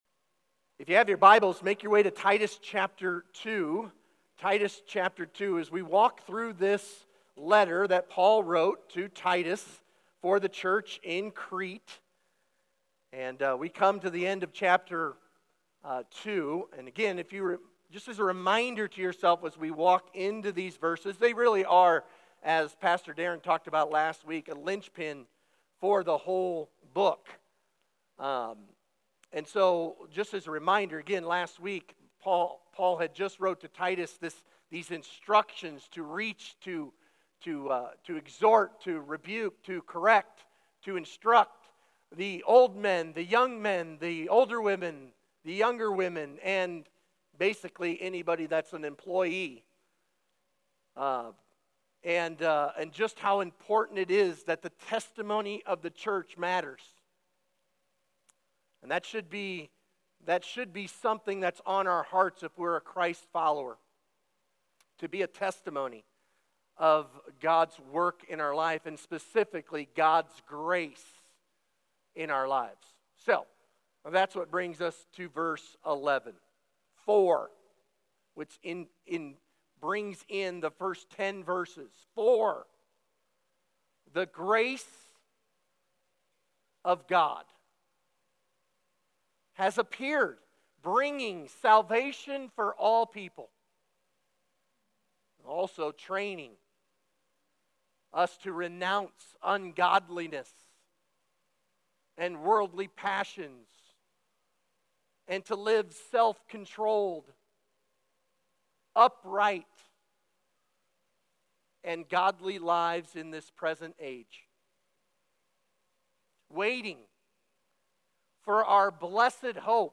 Sermon Discussion: Read the Passage (Titus 2:11–15).